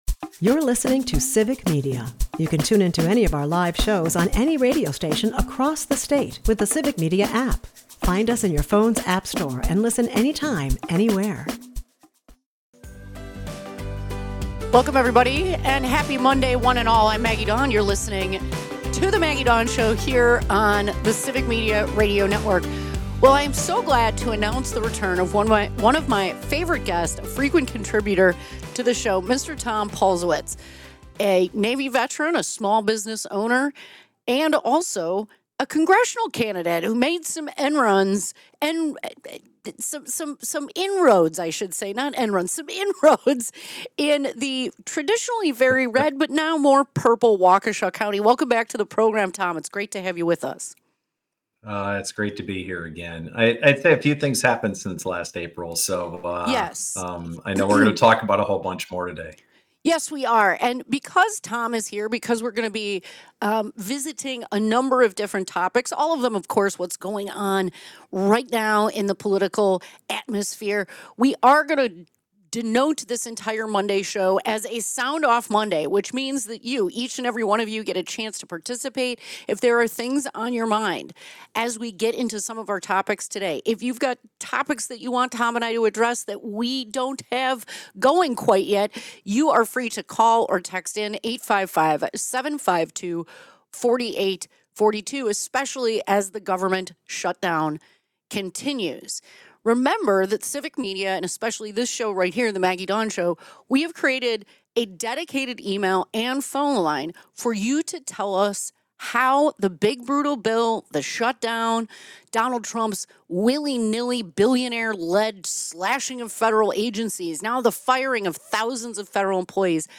The discussion expands to the Trump administration’s dubious crypto connections and questionable Middle Eastern ties, painting a troubling picture of influence and greed. Meanwhile, callers voice outrage over military families forced into food lines during the government shutdown, condemning both congressional dysfunction and the administration’s deepening ethical void.